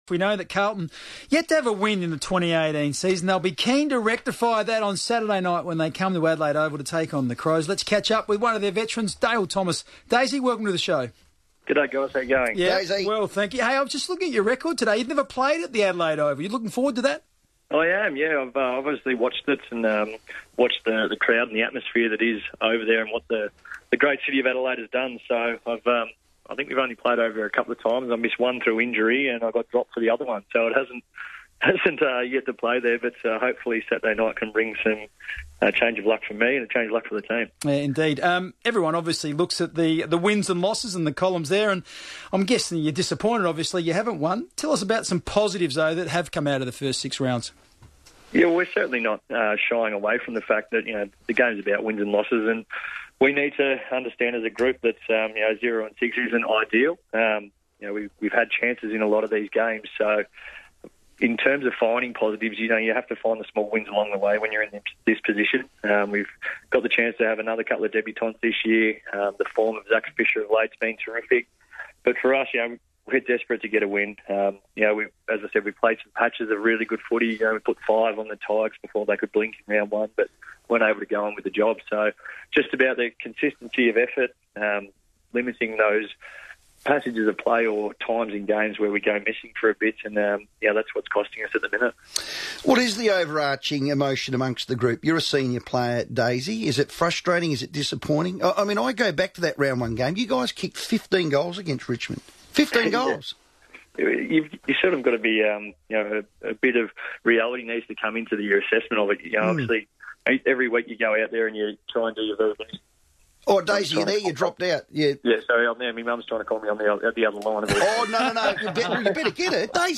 Carlton's Dale Thomas speaks to Adelaide radio station FIVEaa ahead of the Blues' clash against the Crows.